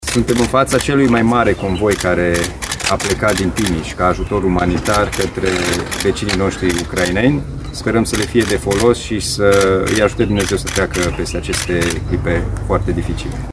Administrația județeană a donat 250.000 de lei din fondul de rezervă pentru achiziționarea de produse farmaceutice, medicamente, dezinfectanți lenjerie de pat, saltele și alimente neperisabile, spune președintele Consiliului Județean Timiș, Alin Nica: